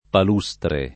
[ pal 2S tre ]